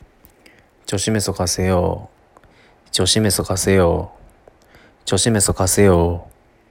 「お気をつけて」の発音